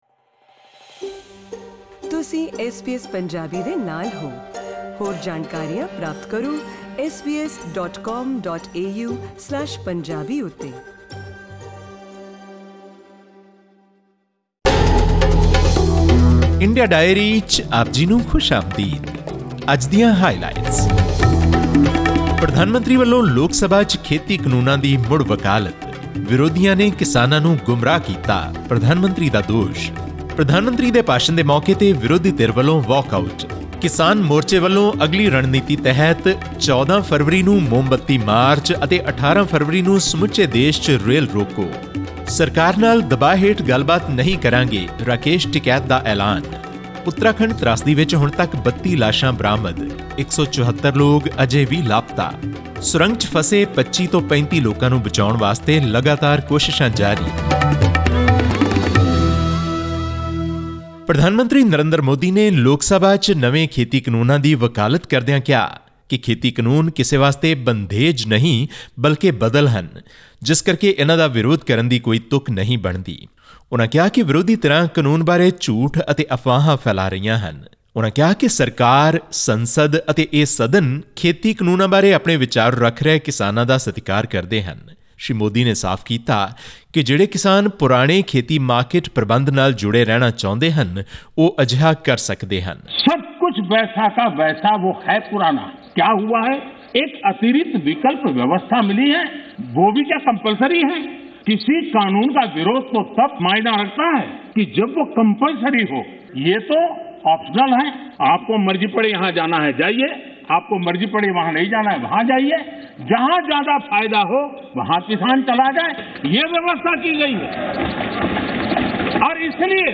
Addressing the Lok Sabha on Wednesday, Prime Minister Narendra Modi expressed the government's respect towards the agitating farmers and added that the government would continue to hold discussions with the farmers' unions. This and more in our weekly news segment from India.